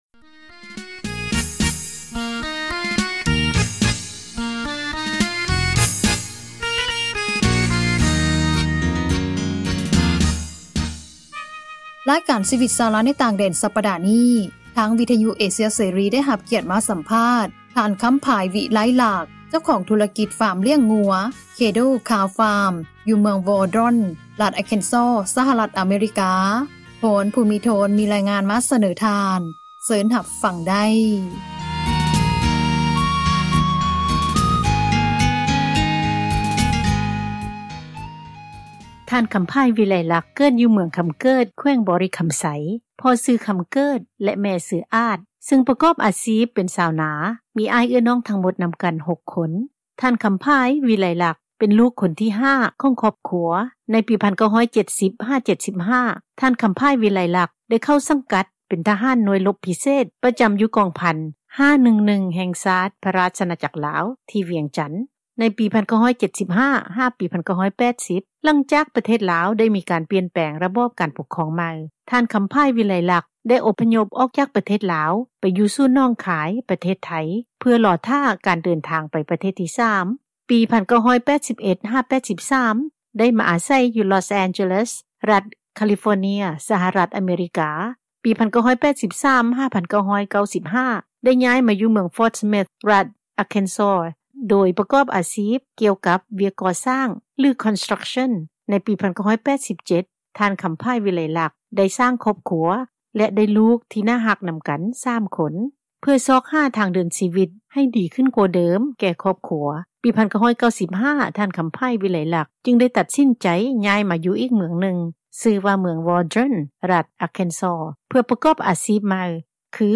ສັມພາດ